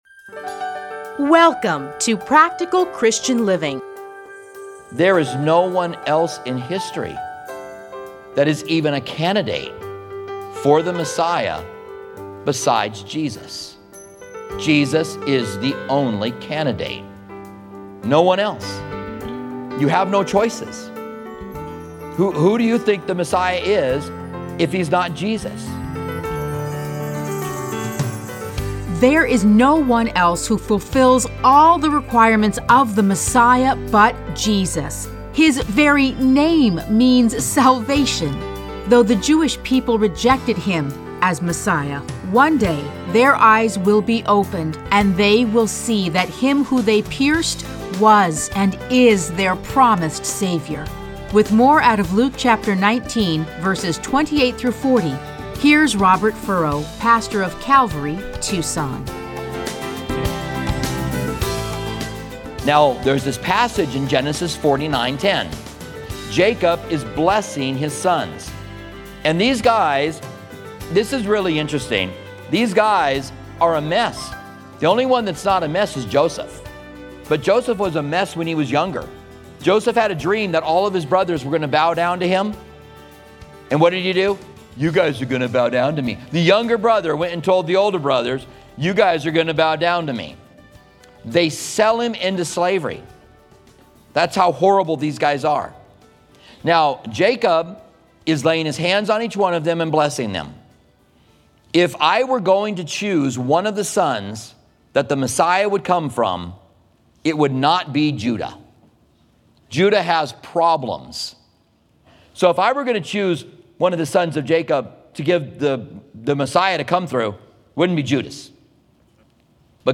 Listen to a teaching from Luke 19:28-40.